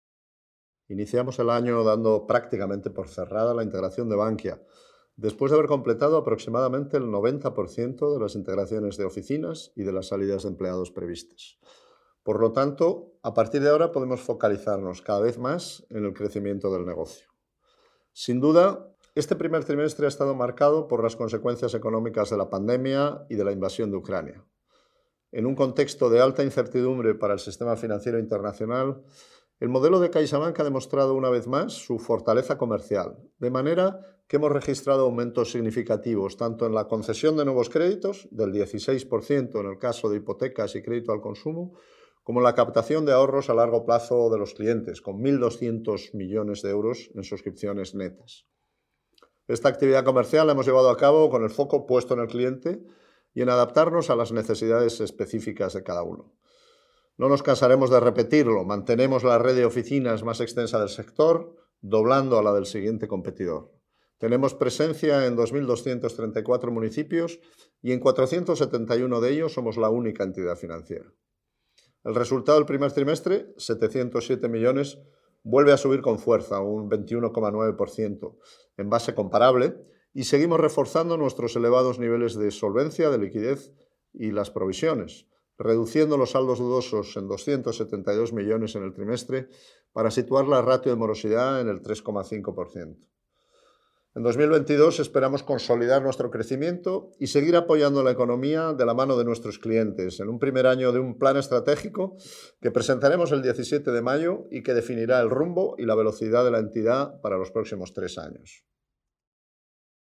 en la presentación de resultados